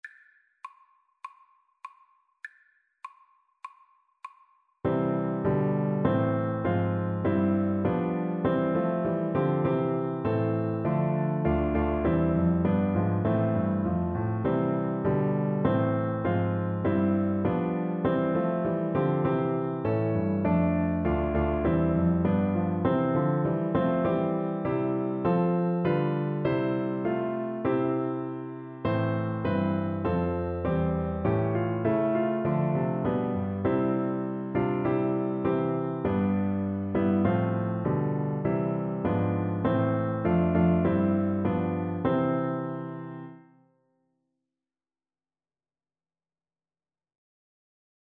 Play (or use space bar on your keyboard) Pause Music Playalong - Piano Accompaniment Playalong Band Accompaniment not yet available reset tempo print settings full screen
Moderato
4/4 (View more 4/4 Music)
C major (Sounding Pitch) (View more C major Music for Oboe )
Traditional (View more Traditional Oboe Music)